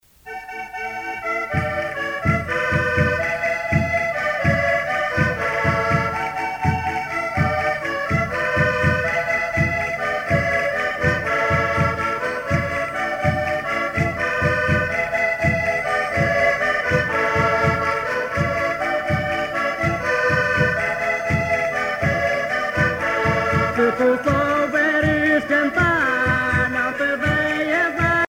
danse : vira (Portugal)
Grupo folclorico da Casa do Concelho de Arcos de Valdevez
Pièce musicale éditée